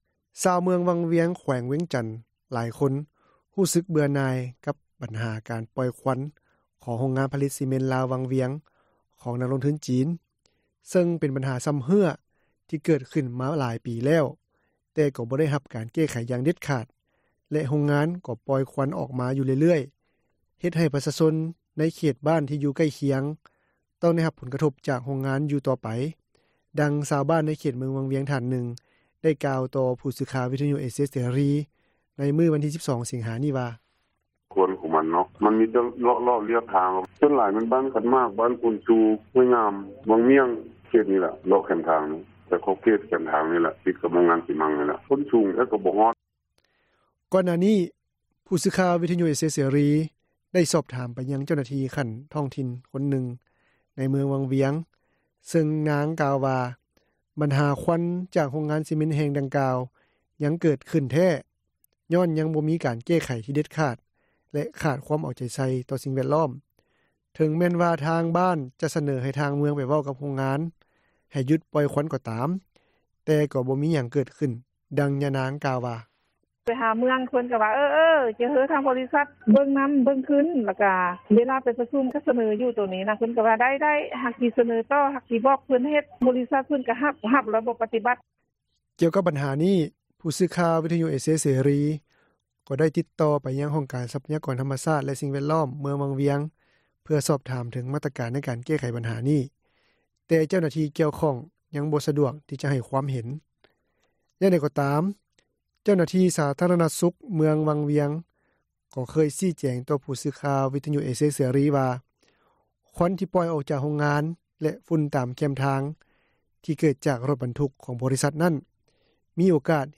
ຊາວເມືອງວັງວຽງແຂວງວຽງຈັນ ຫຼາຍຄົນຮູ້ສຶກເບື່ອໜ່າຍ ກັບບັນຫາການປ່ອຍຄວັນ ຂອງໂຮງງານຜລິຕຊີເມັນລາວວັງວຽງ ຂອງນັກລົງທຶນ ຈີນ, ເຊິ່ງເປັນບັນຫາຊຳເຮື້ອ ທີ່ເກີດຂຶ້ນມາຫຼາຍປີແລ້ວ, ແຕ່ກໍບໍ່ໄດ້ຮັບການແກ້ໄຂ ຢ່າງເດັດຂາດ ແລະ ໂຮງງານ ກໍປ່ອຍຄວັນອອກມາຢູ່ ເລື້ອຍໆ ເຮັດໃຫ້ປະຊາຊົນ ໃນເຂດບ້ານທີ່ຢູ່ໃກ້ຄຽງ ຕ້ອງໄດ້ຮັບຜົລກະທົບ ຈາກໂຮງງານຢູ່ຕໍ່ໄປ, ດັ່ງຊາວບ້ານໃນເຂດເມືອງວັງວຽງ ທ່ານນຶ່ງ ໄດ້ກ່າວຕໍ່ຜູ້ສື່ຂ່າວວິທຍຸເອເຊັຽເສຣີ ໃນມື້ວັນທີ 12 ສິງຫາ ນີ້ວ່າ: